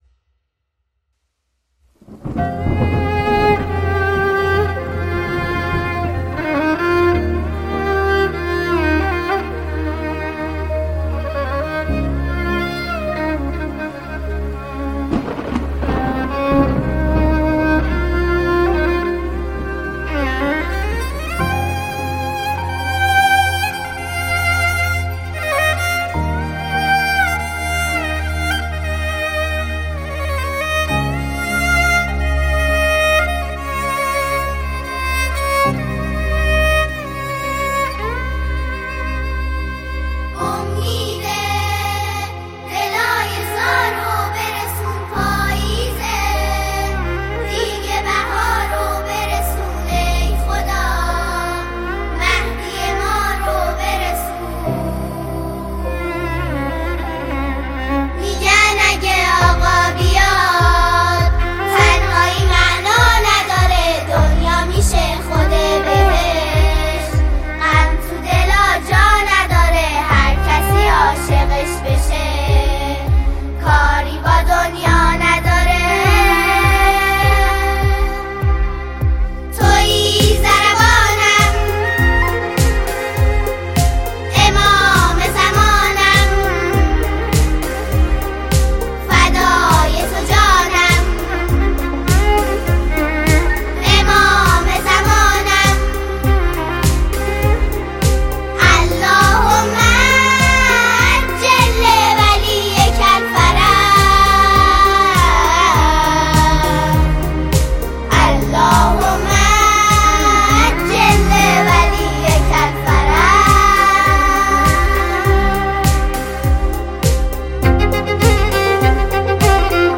سرود معنوی و پرشور
جمعی از نوجوانان عاشق، با صدایی هماهنگ
ژانر: سرود